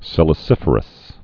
(sĭlĭ-sĭfər-əs)